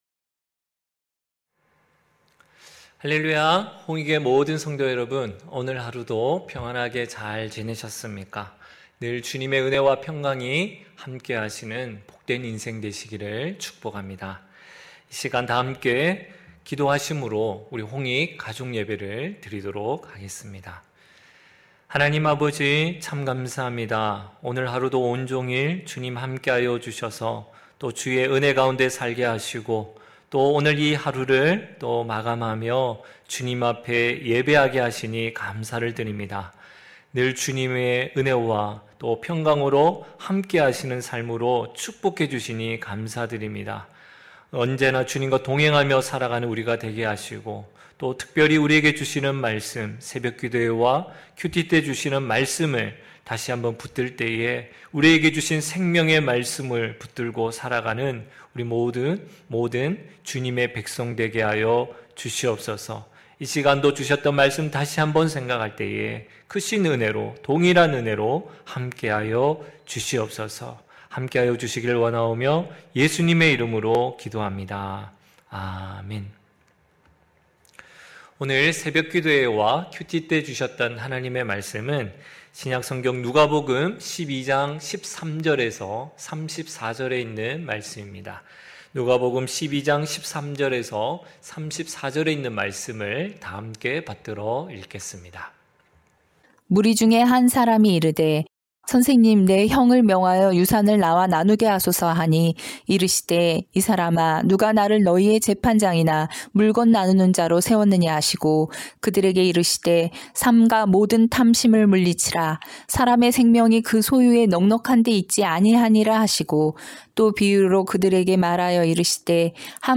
9시홍익가족예배(2월8일).mp3